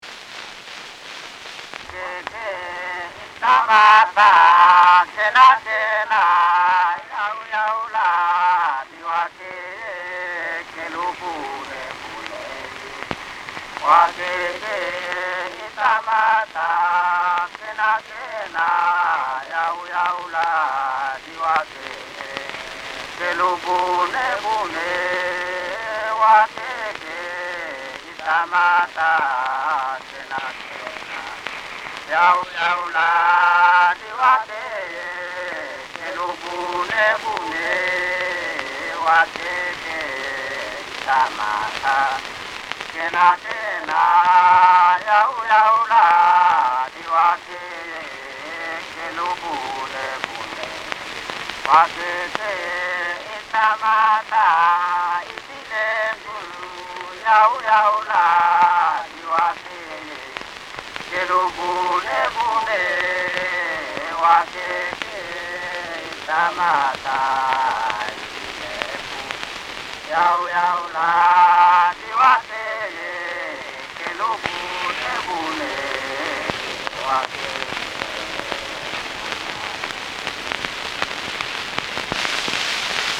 Bwaidogan kadede: a dance song
a trio from the Bwaidoka district of Goodenough Island.
wax cylinder recordings of songs and spoken language (principally Bwaidoka)
on Goodenough Island (D'Entrecasteaux Islands) in Papua New Guinea in 1912